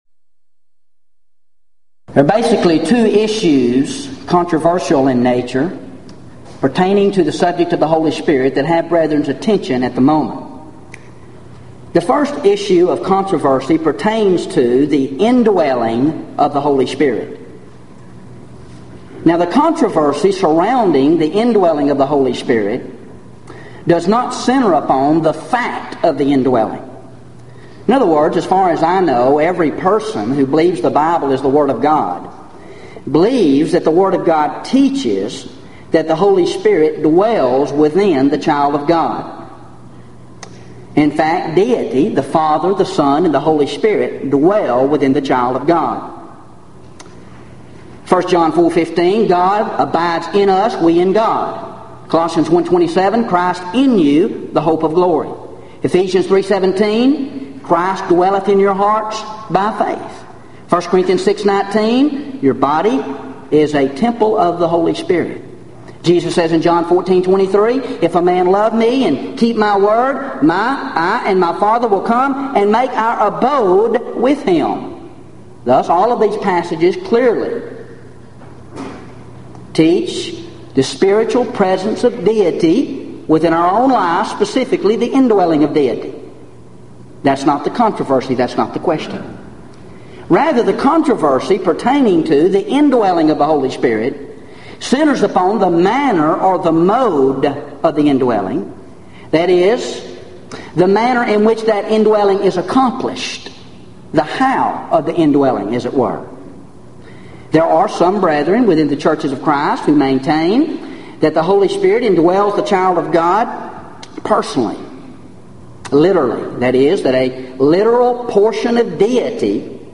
Event: 1997 Gulf Coast Lectures